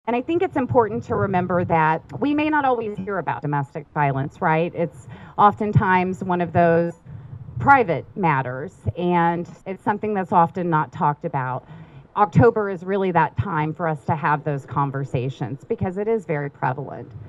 A Silent Witness Ceremony was held at the Riley County Courthouse Plaza Wednesday over the lunch hour.